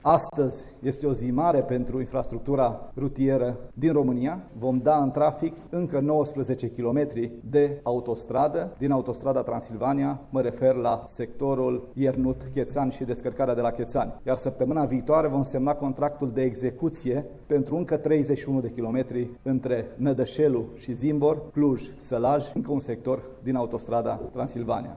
Prezent la inaugurarea tronsonului Iernut –Chețani, ministrul transporturilor Lucian Bode, a ținut să sublinieze: